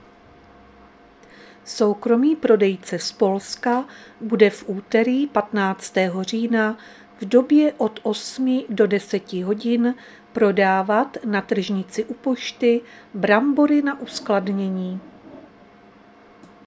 Záznam hlášení místního rozhlasu 14.10.2024
Zařazení: Rozhlas